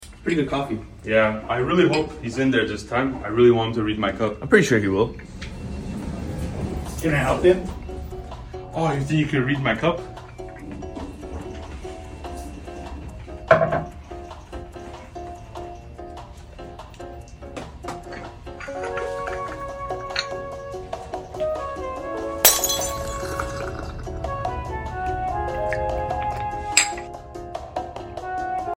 ✨Priest Reading a Coffee Cup✨ sound effects free download